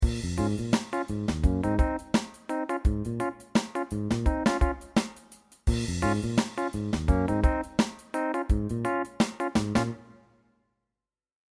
Lydeksempel uten mellomstemmer (kun bass og trommer)